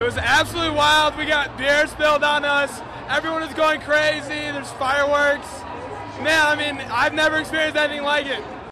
Listen to the fans of the Braves and their reactions to winning the World Series: